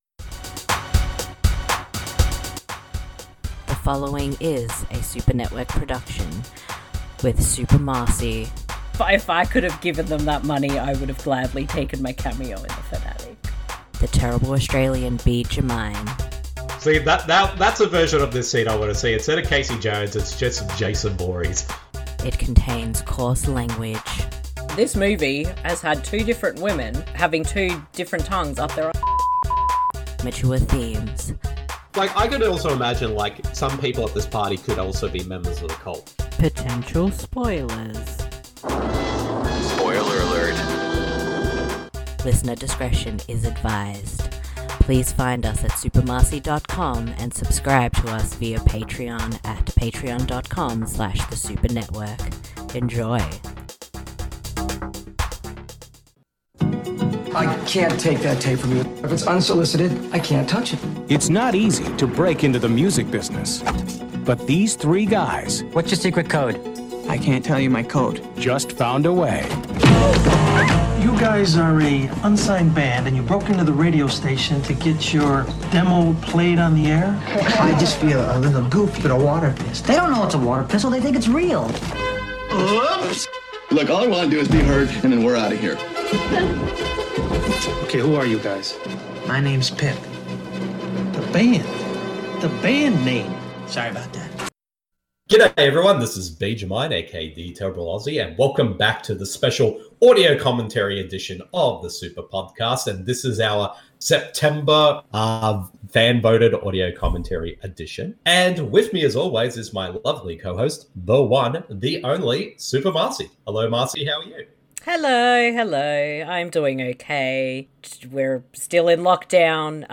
You simply need to grab a copy of the film, and sync up the podcast audio with the film.